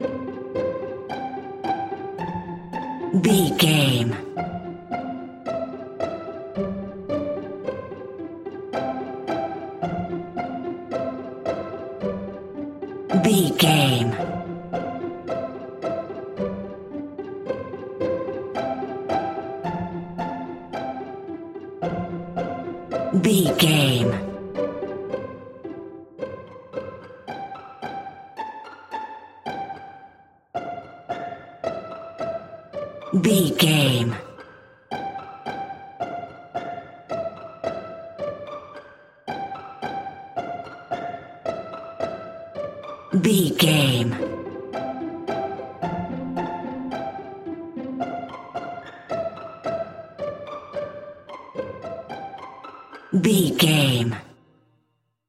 Ionian/Major
nursery rhymes
childrens music